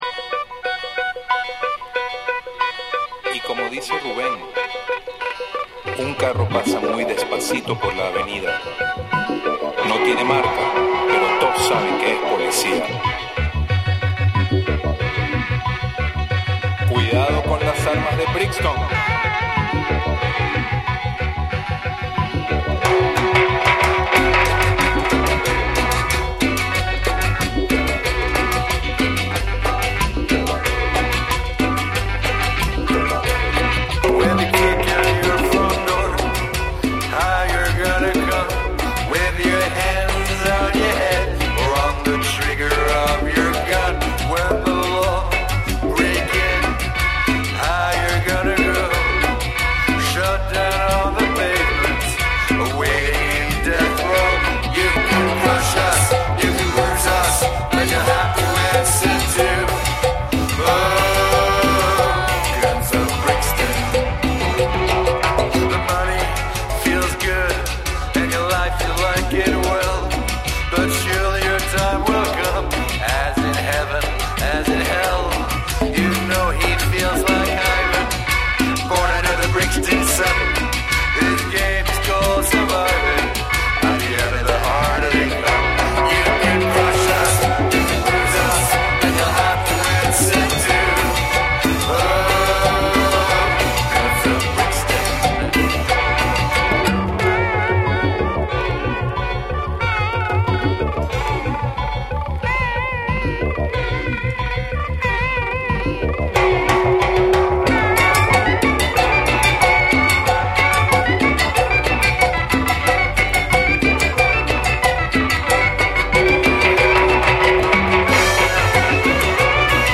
クンビアにサイケデリックでサーフロックに通じるギターのメロディーが融合したジャンル